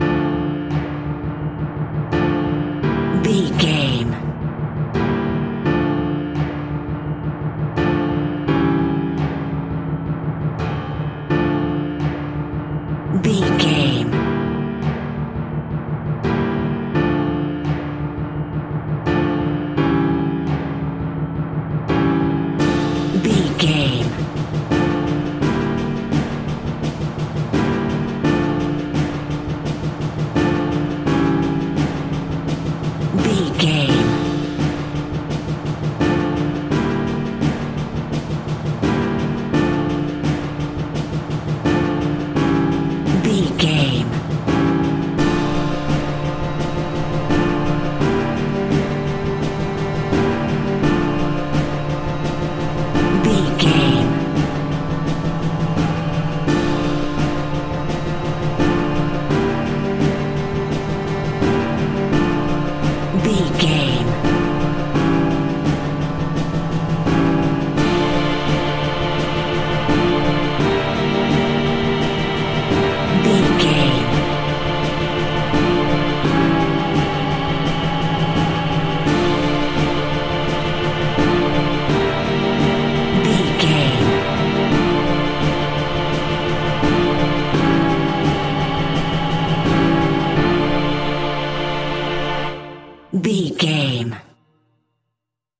In-crescendo
Thriller
Aeolian/Minor
scary
ominous
dark
haunting
eerie
piano
percussion
synth
ambience
pads